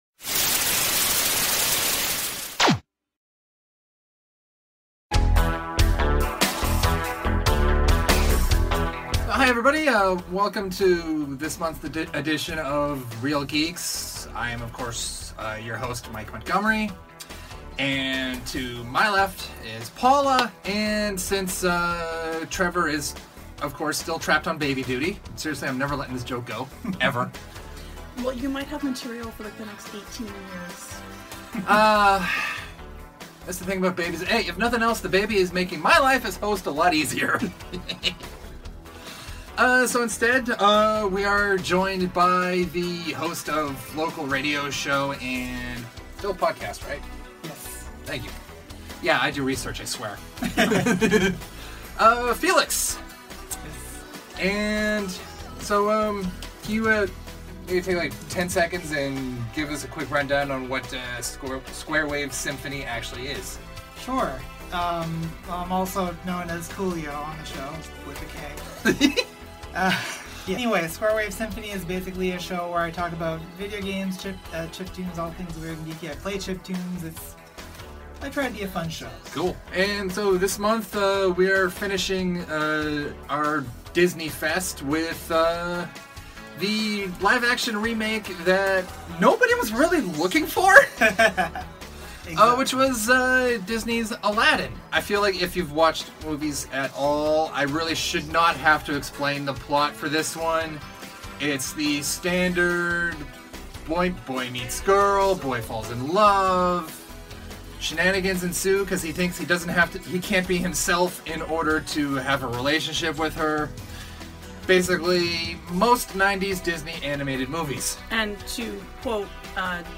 Originally recorded in Halifax, NS, Canada